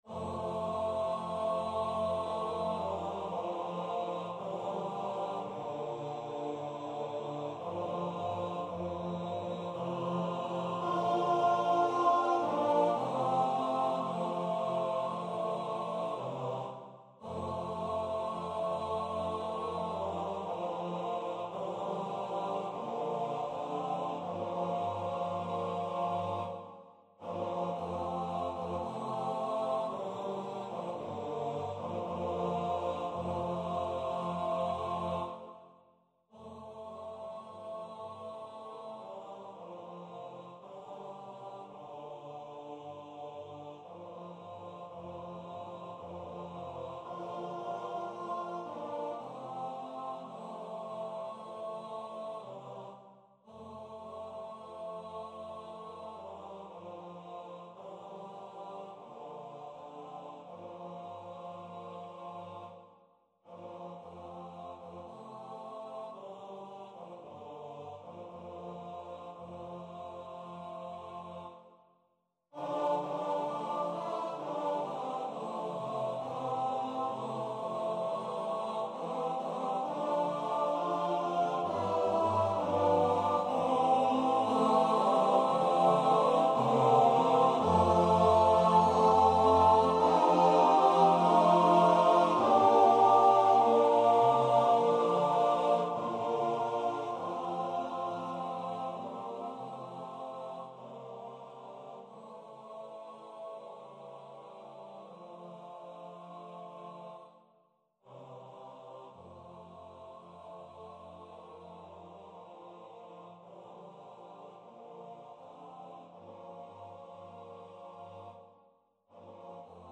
Number of voices: 4vv Voicing: TTBB Genre: Sacred, Barbershop
Language: English Instruments: A cappella
Description: Traditional spiritual, arranged by Philip Le Bas External websites: Original text and translations English text 1.